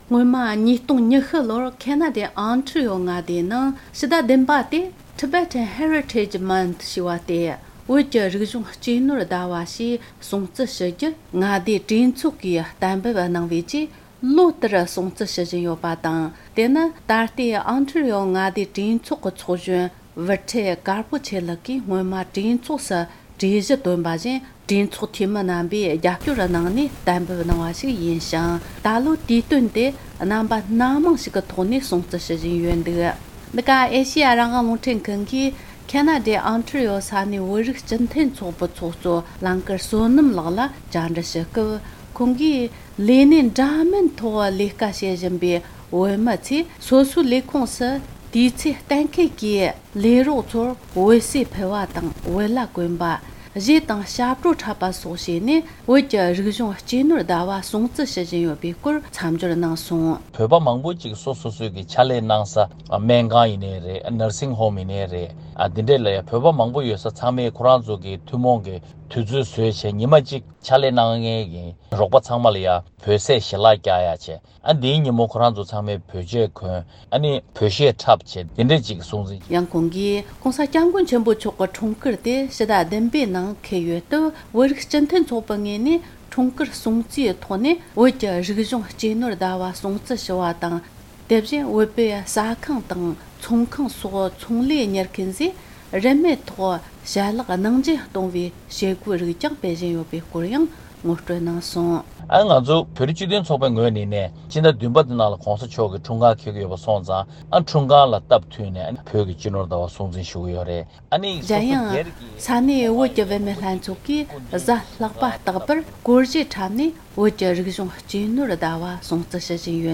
འབྲེལ་མི་སྣར་བཅར་འདྲི་ཞུས་ཏེ་གནས་ཚུལ་ཕྱོགས་བསྡུས་བྱས་པར་གསན་རོགས་གནོངས།